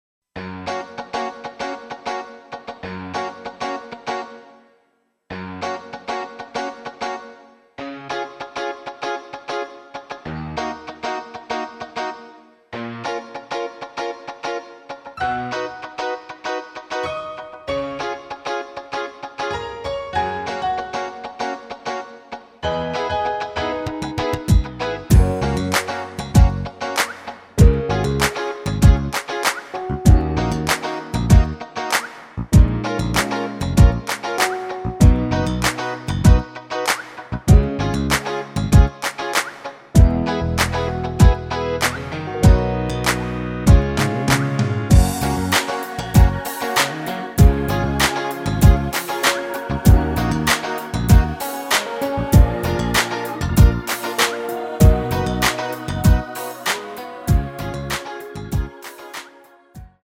F#
◈ 곡명 옆 (-1)은 반음 내림, (+1)은 반음 올림 입니다.
앞부분30초, 뒷부분30초씩 편집해서 올려 드리고 있습니다.